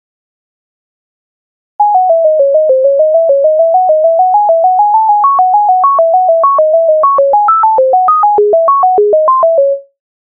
MIDI файл завантажено в тональності cis-moll